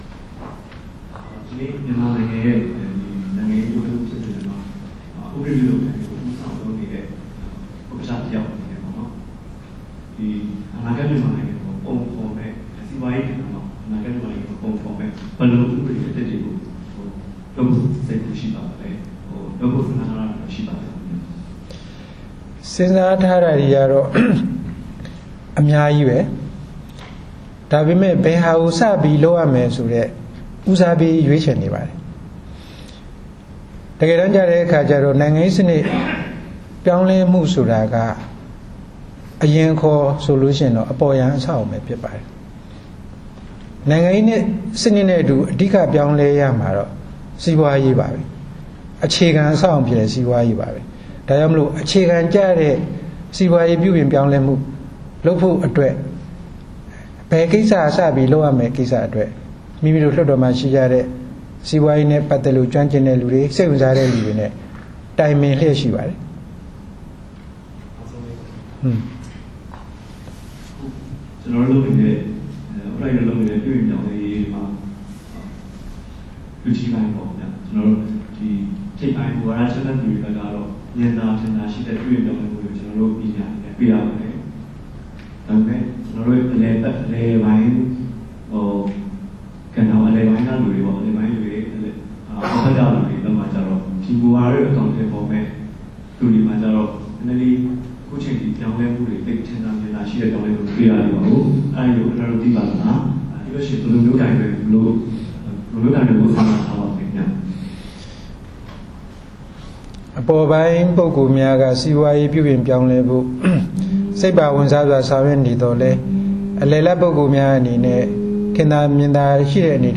ပြည်သူ့လွှတ်တော် ဥက္ကဋ္ဌ သူရ ဦးရွှေမန်း သတင်းစာ ရှင်းလင်း